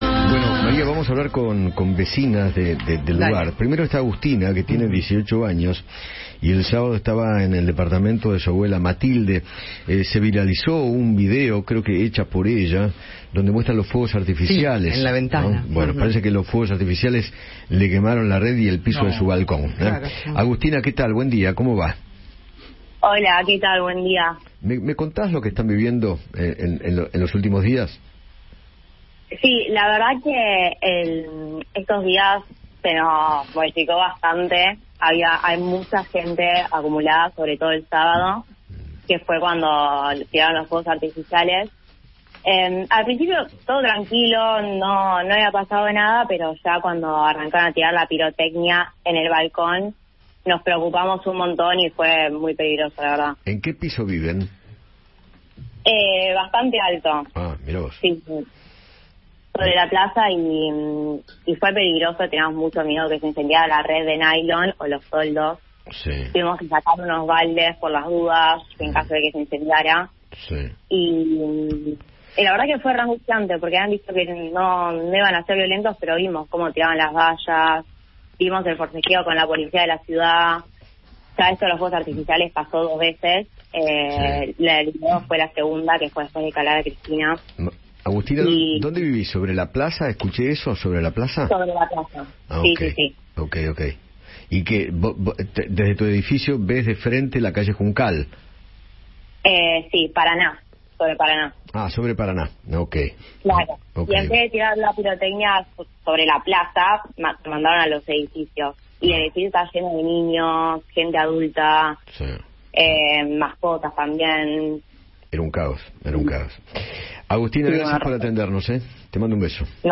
Eduardo Feinmann habló con dos vecinas de la zona de Recoleta, quienes se vieron afectadas por las manifestaciones en apoyo a Cristina Kirchner, y relataron la vandalización de propiedades o agravios verbales que sufrieron el sábado.